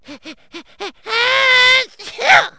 sneeze1.wav